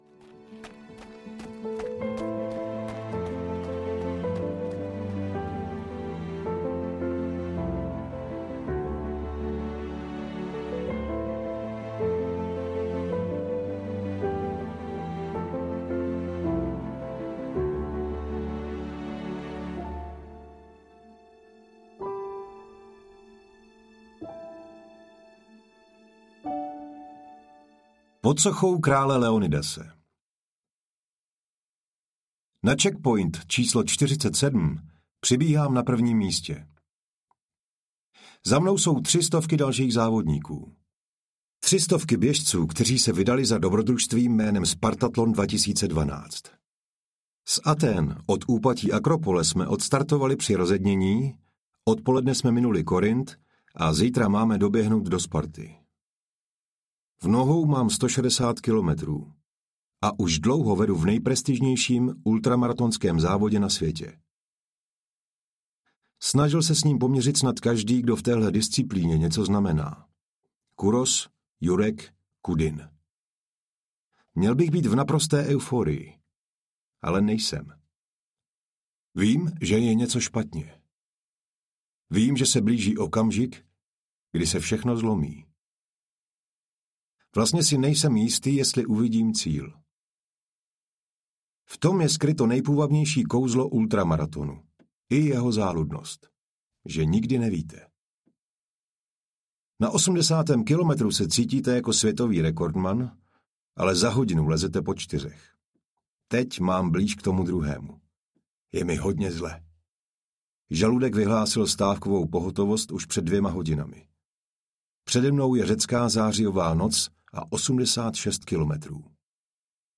Můj dlouhý běh audiokniha
Ukázka z knihy